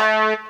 GUnit Synth13.wav